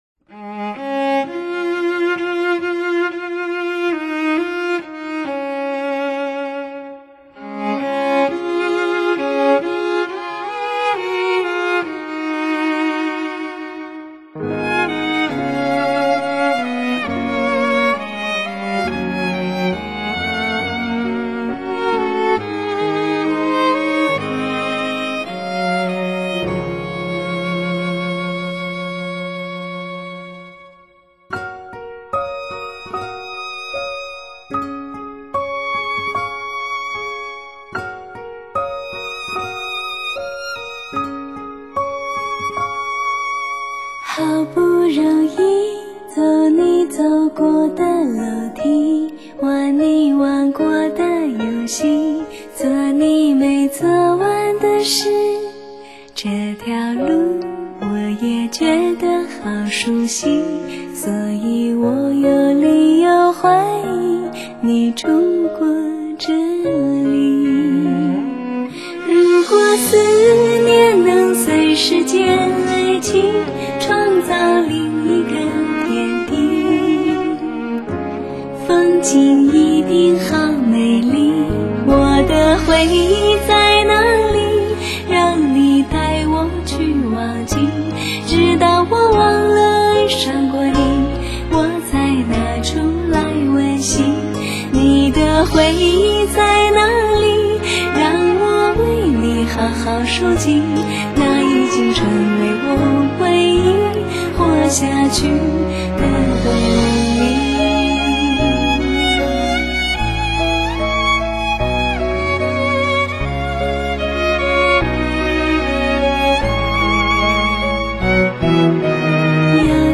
本碟的演奏与人声都非常精彩动听，
从头听到尾都洋溢着一股悠闲的芬芳，
曲中音色力度以至情绪的变化幅度都甚大，
激情之余又能保持高度的流畅感，